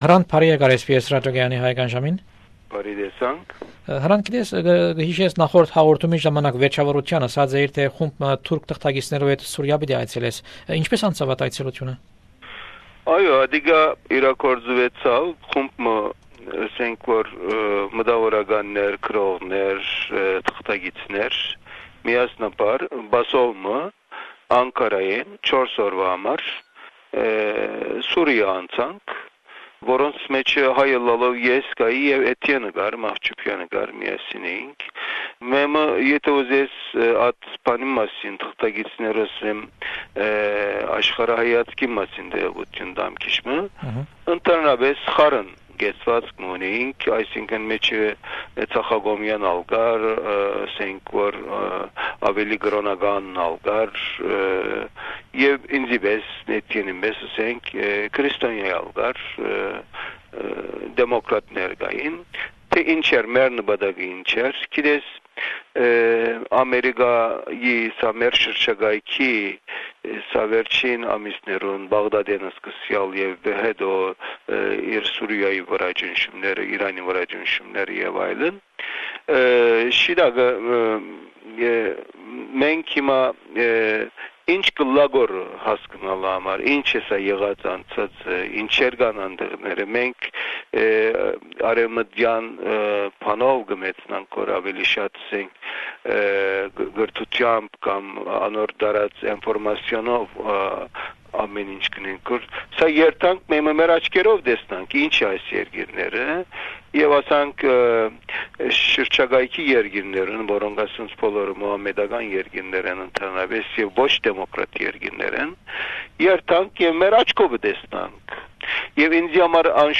This is the third interview with Hrant Dink (3 June 2003). Hrant talks about his visit to Syria with a group of turkish journalists.